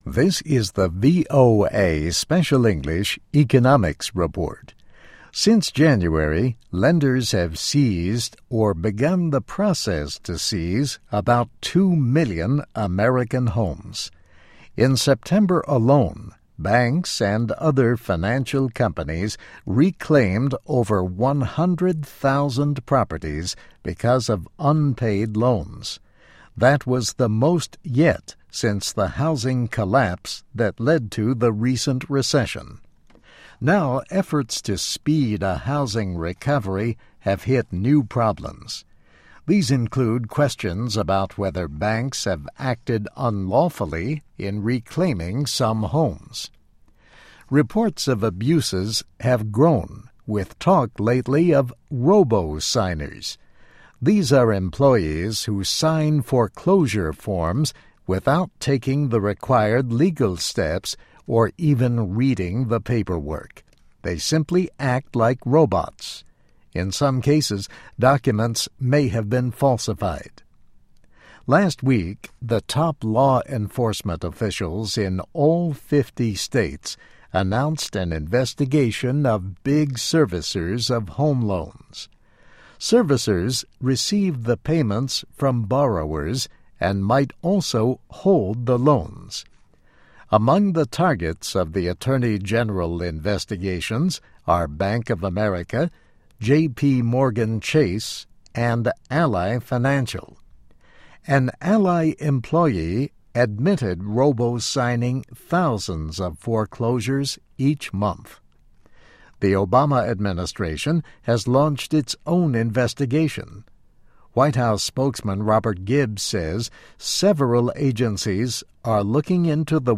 VOA Special English - Text & MP3